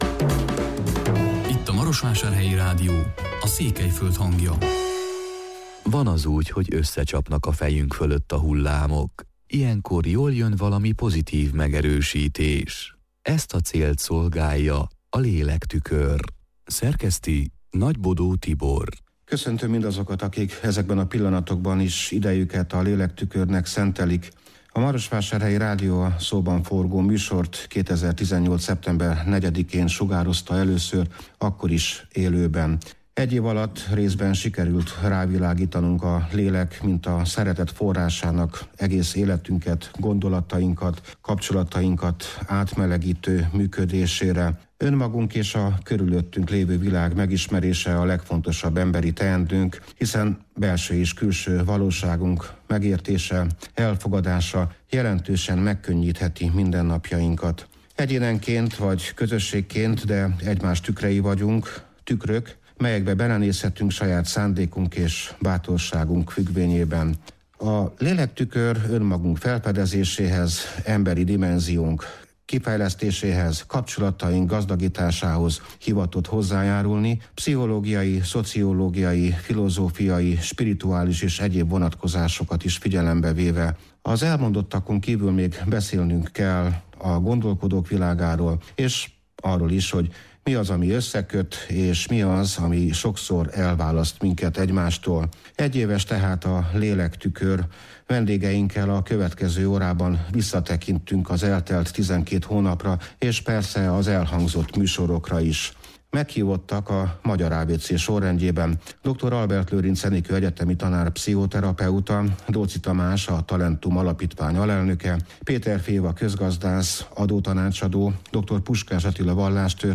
Vendégeinkkel visszatekintünk az eltelt tizenkét hónapra és az elhangzott műsorokra…